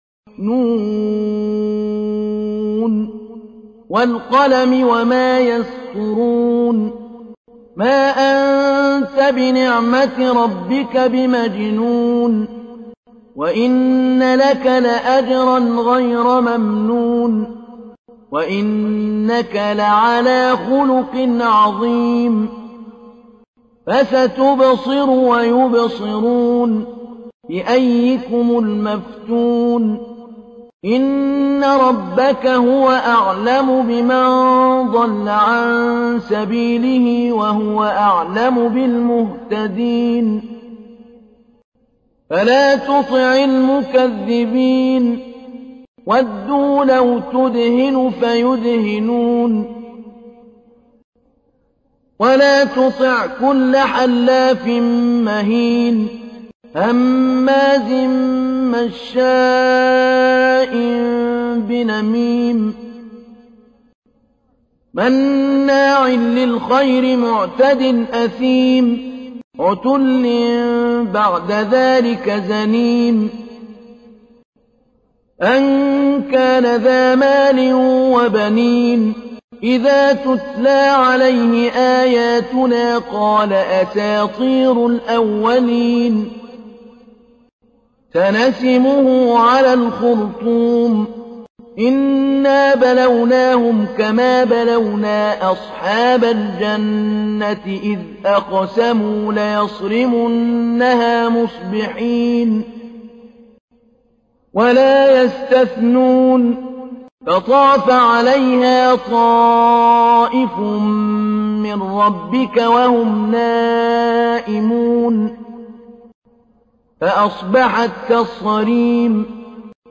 تحميل : 68. سورة القلم / القارئ محمود علي البنا / القرآن الكريم / موقع يا حسين